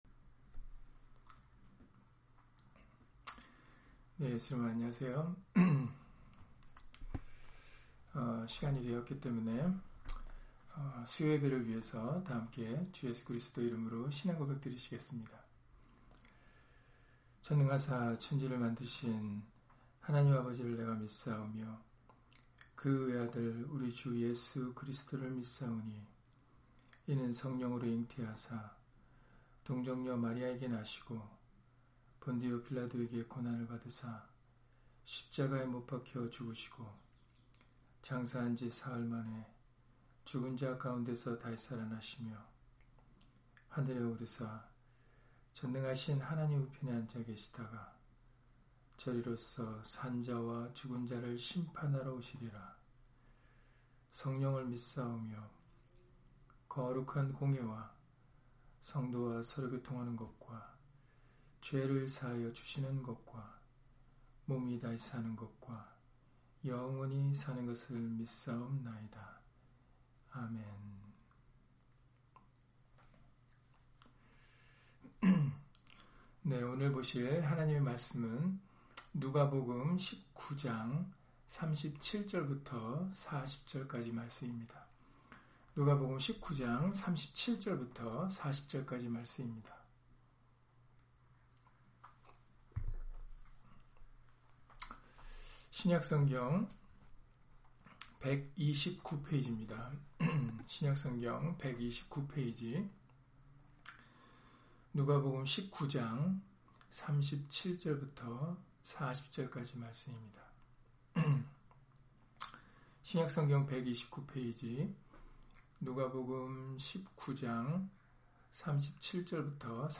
누가복음 19장 37-40절 [찬송을 받으시기에 합당하신 예수님] - 주일/수요예배 설교 - 주 예수 그리스도 이름 예배당